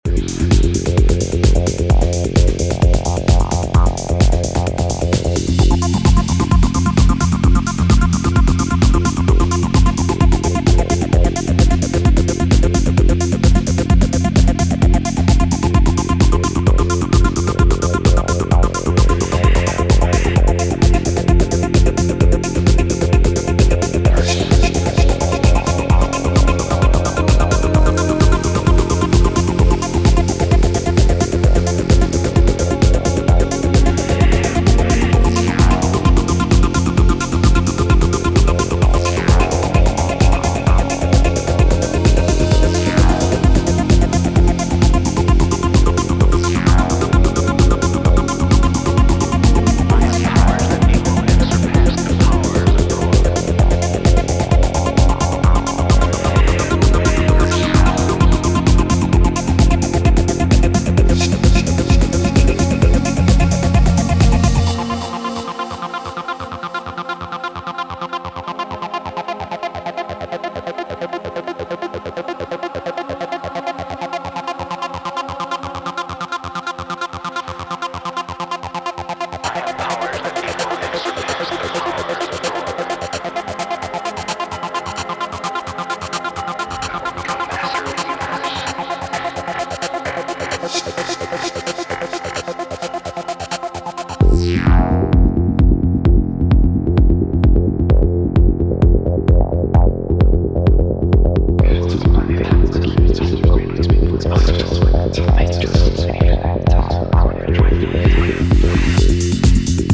a four-track EP from label